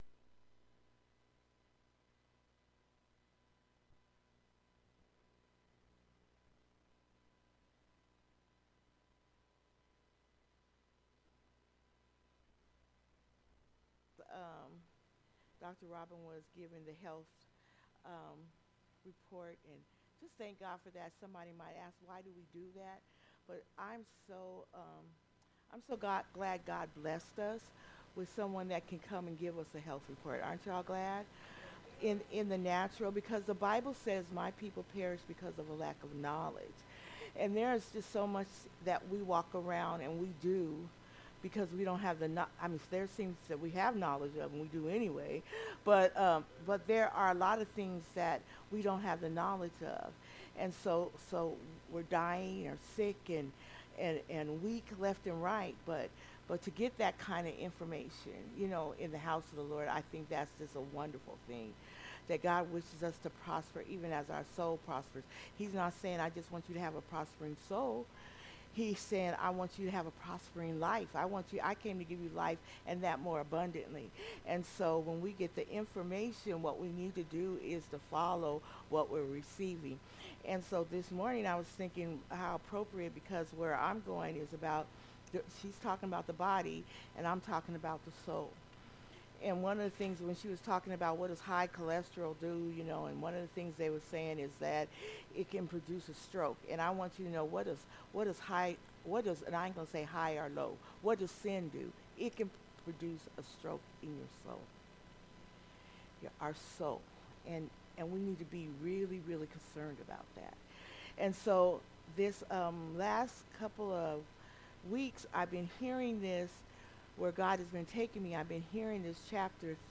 Sermons by Living Faith Christian Center OH